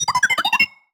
sci-fi_driod_robot_emote_beeps_03.wav